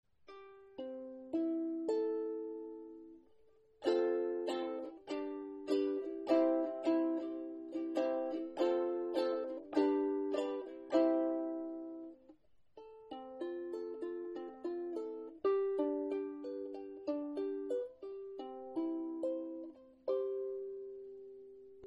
Meine Soundbeispiele sind alle am gleichen Tag mit einem Zoom H2 aufgenommen.
Gespielt habe ich nur ein paar Akkorde.
Korpus massiv Mahagoni,  Worth Saiten